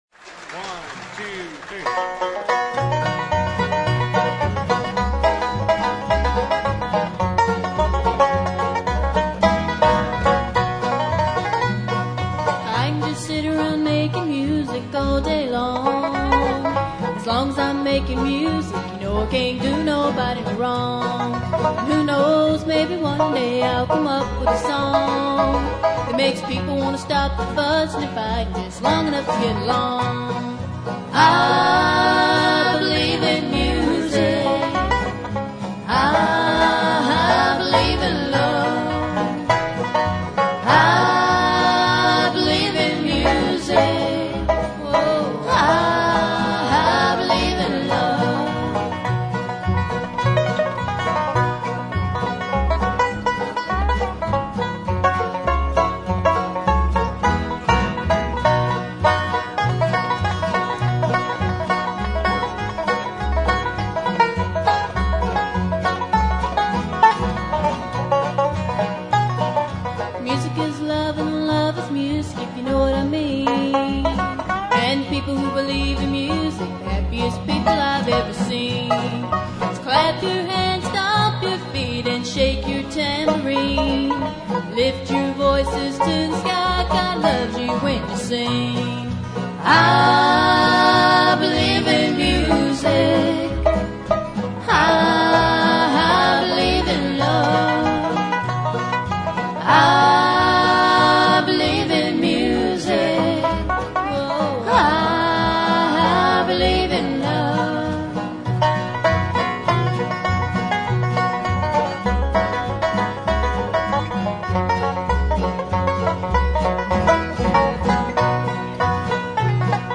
Alexandria Convention Center 1979
Bass